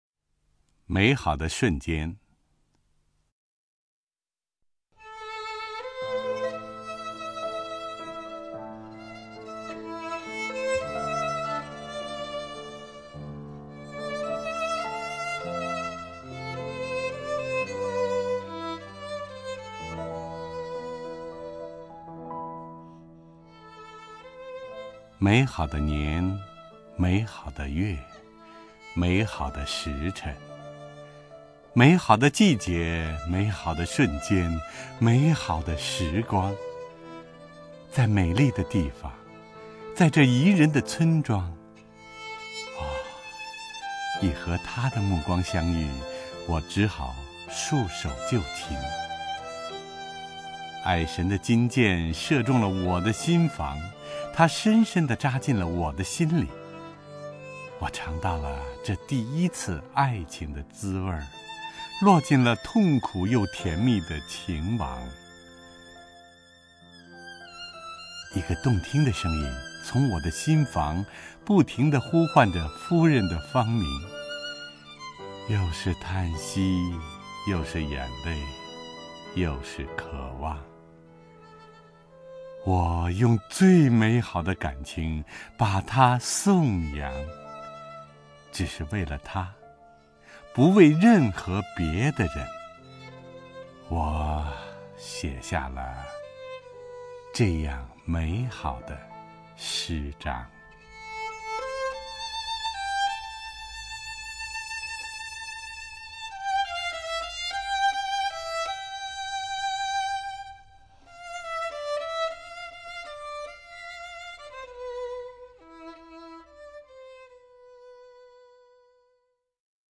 首页 视听 名家朗诵欣赏 陈铎
陈铎朗诵：《美好的瞬间》(（意）弗朗西斯克·彼特拉克)　/ （意）弗朗西斯克·彼特拉克